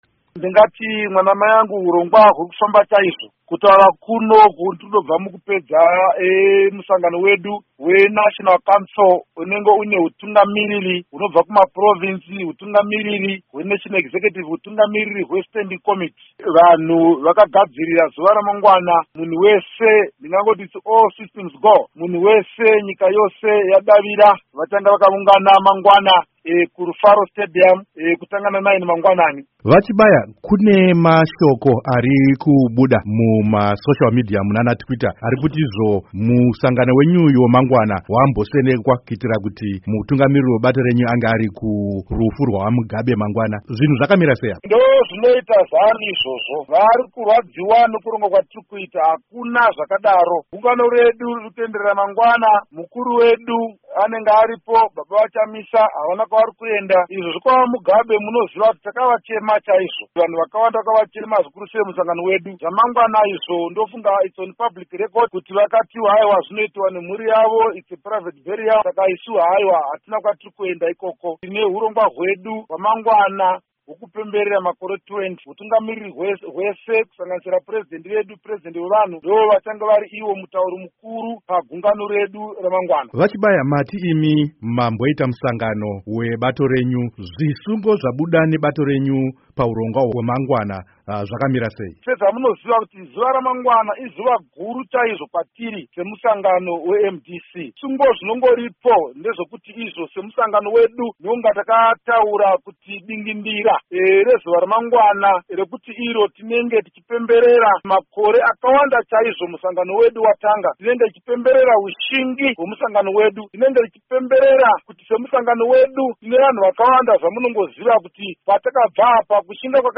Hurukuro naVaAmos Chibaya